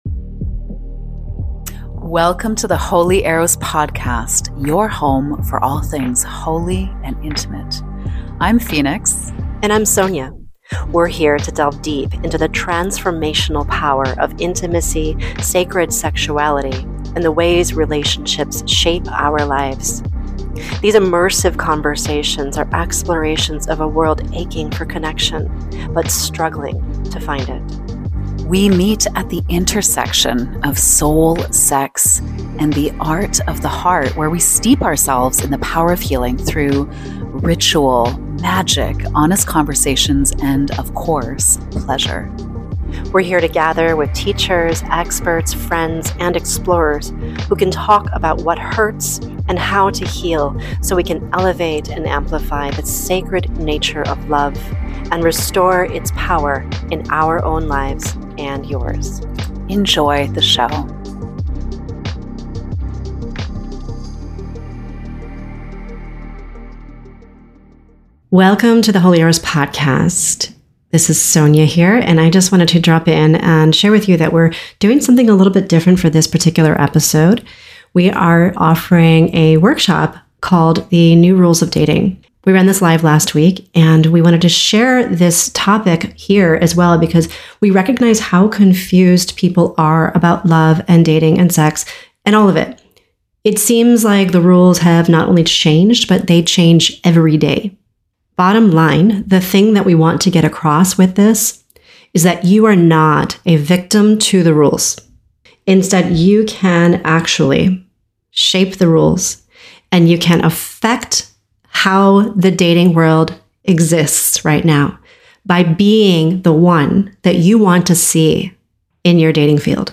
In this episode, we’re condensing the best parts of our recent live workshop, The New Rules of Dating, to share with you some tools for the best ways to navigate a complicated world.